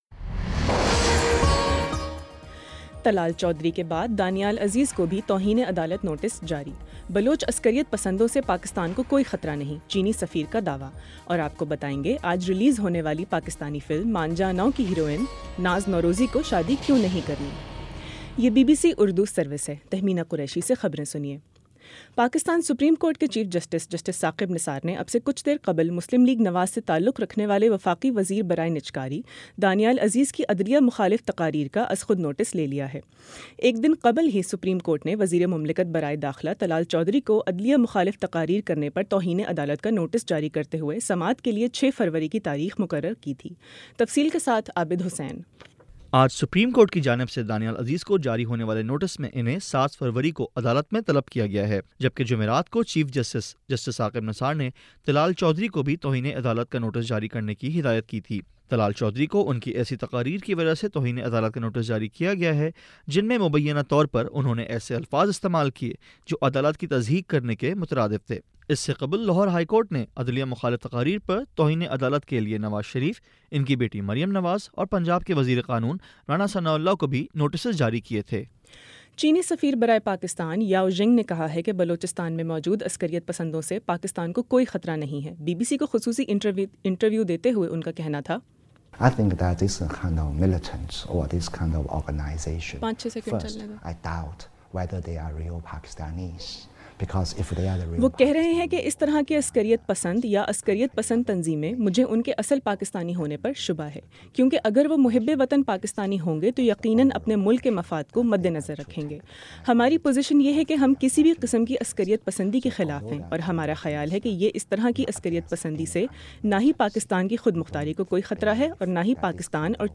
دس منٹ کا نیوز بُلیٹن روزانہ پاکستانی وقت کے مطابق شام 5 بجے، 6 بجے اور پھر 7 بجے۔